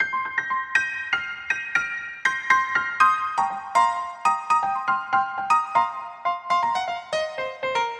爵士乐只有钢琴
描述：爵士乐循环与钢琴
Tag: 120 bpm Jazz Loops Piano Loops 1.35 MB wav Key : Unknown